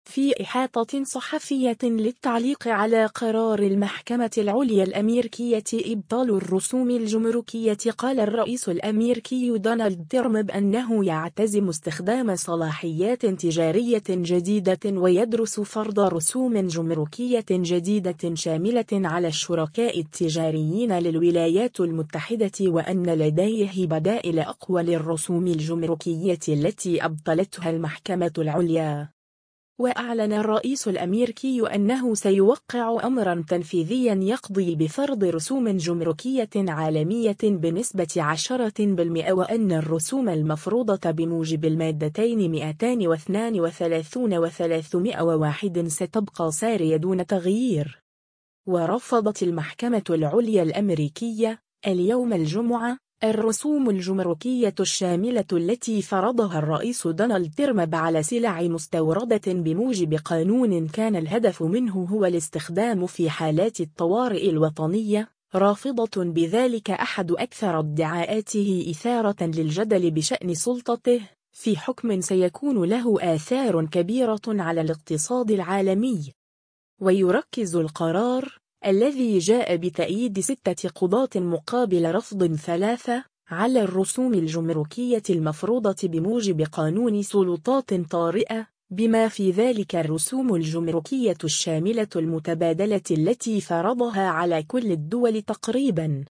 في إحاطة صحفية للتعليق على قرار المحكمة العليا الأميركية إبطال الرسوم الجمركية قال الرئيس الأميركي دونالد ترمب أنه يعتزم استخدام صلاحيات تجارية جديدة ويدرس فرض رسوم جمركية جديدة شاملة على الشركاء التجاريين للولايات المتحدة وأن لديه بدائل أقوى للرسوم الجمركية التي أبطلتها المحكمة العليا.